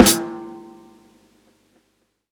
Live_snr.wav